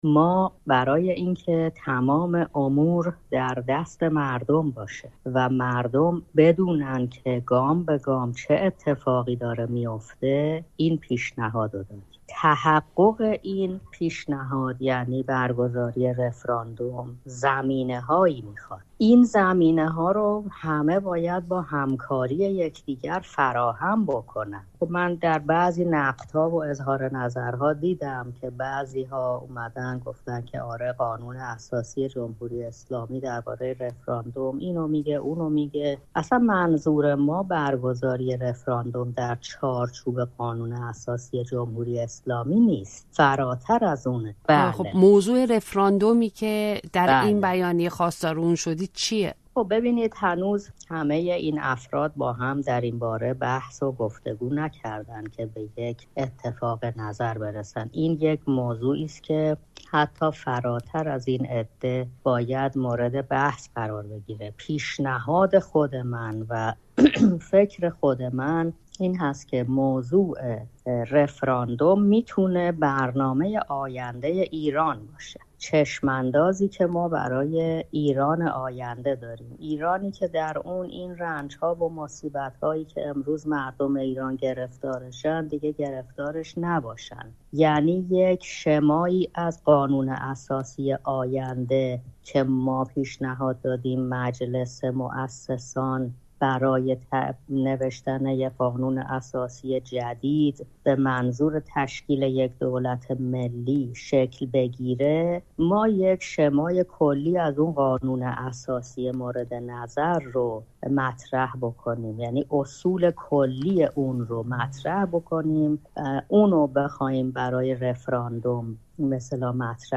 پیشنهاد رفراندوم در ایران و الزامات آن در گفت‌وگو با صدیقه وسمقی